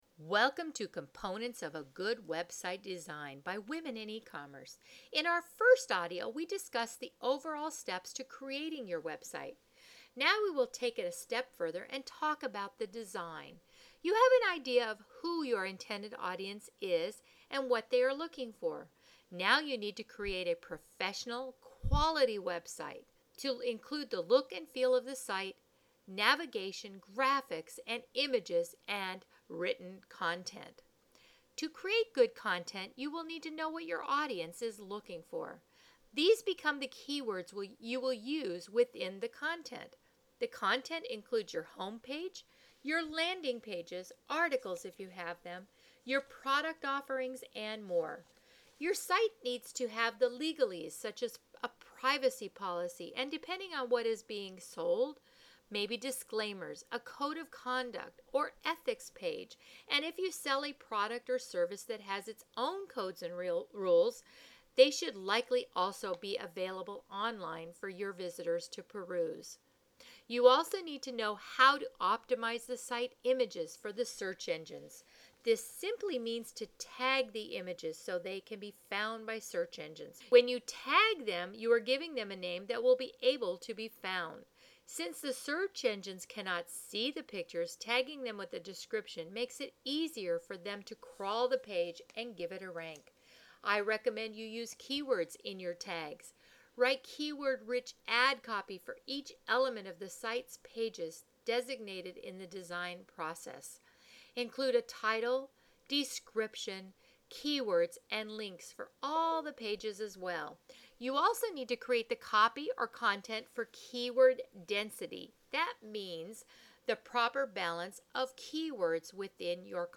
NEW AUDIO Quickcast by Women in Ecommerce ~ Components of a Good Website Design